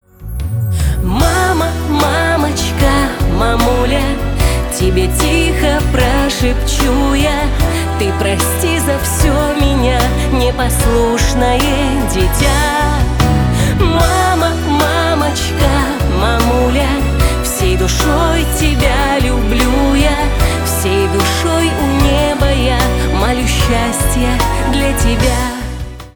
Красивые Медленные Душевные
Поп